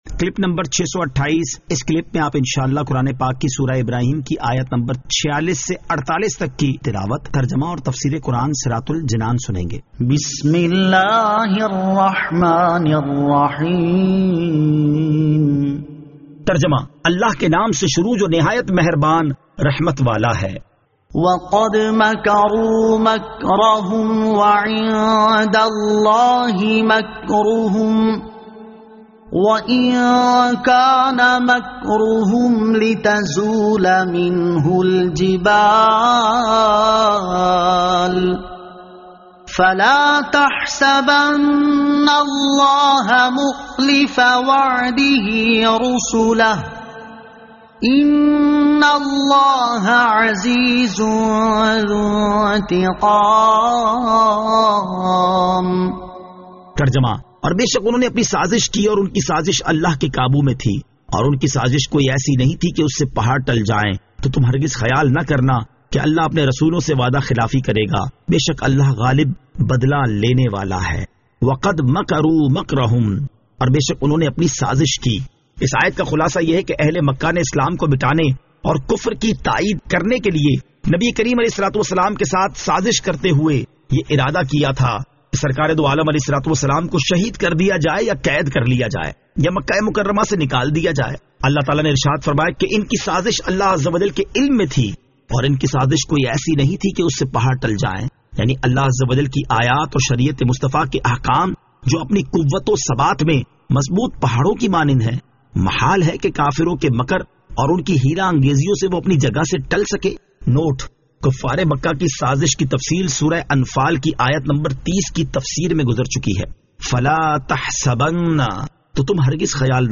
Surah Ibrahim Ayat 46 To 48 Tilawat , Tarjama , Tafseer